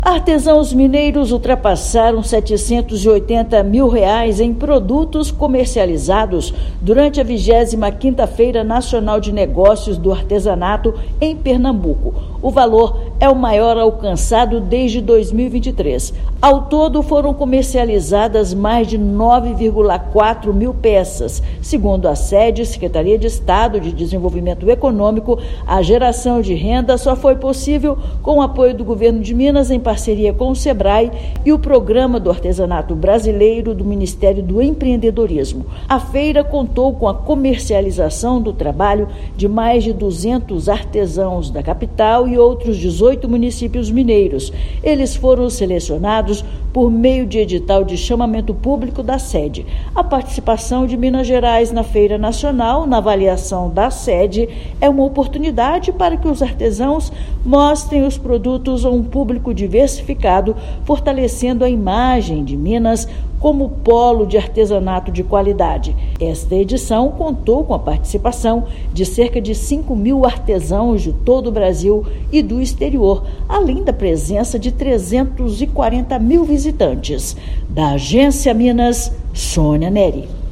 Faturamento foi maior em relação às duas últimas edições da Fenearte; ação beneficiou mais de 200 artesãos. Ouça matéria de rádio.